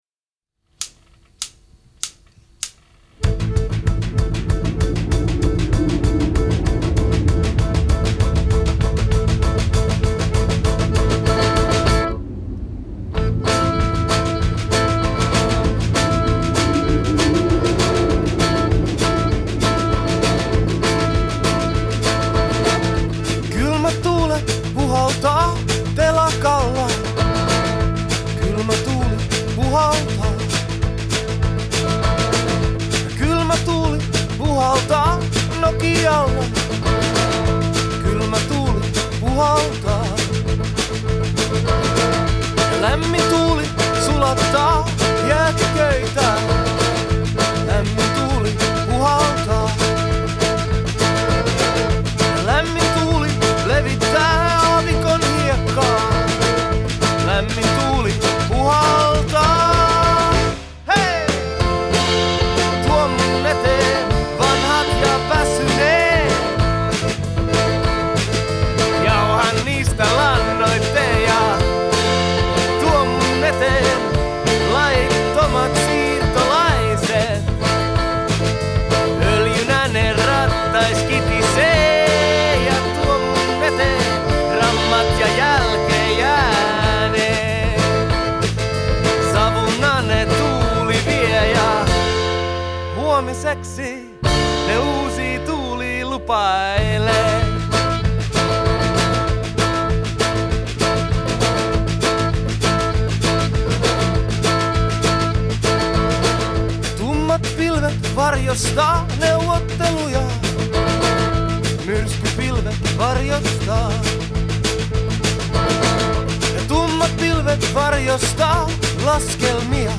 вокал и гитара
аккордеон и вокал
- барабаны
Genre: Folk, World